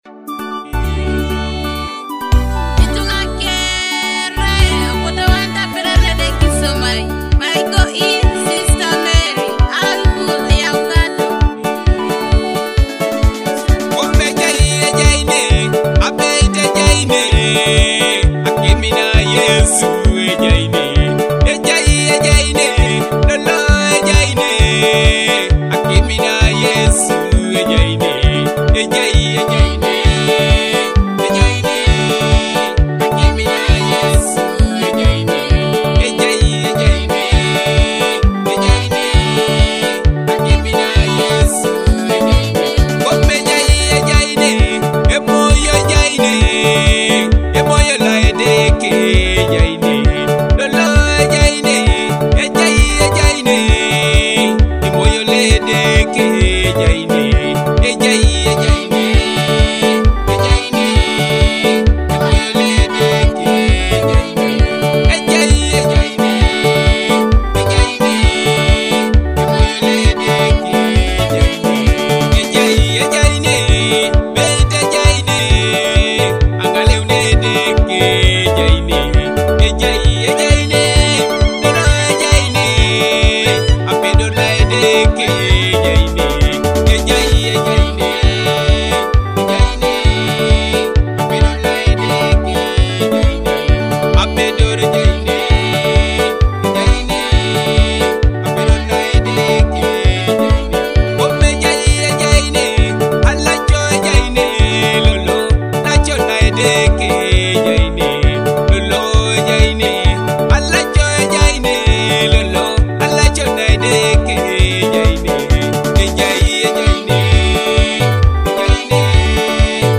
uplifting melody